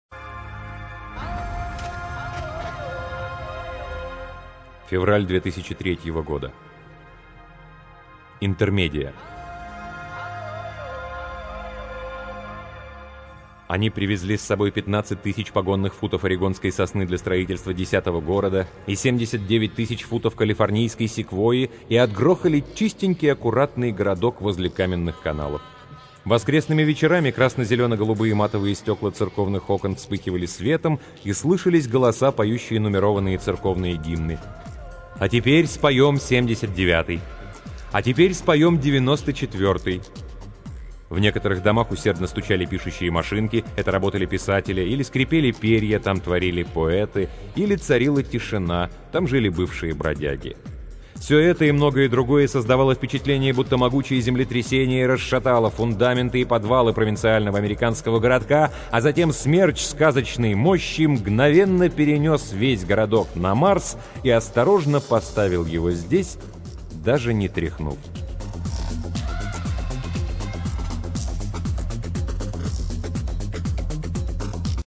Аудиокнига Рэй Брэдбери — Интермедия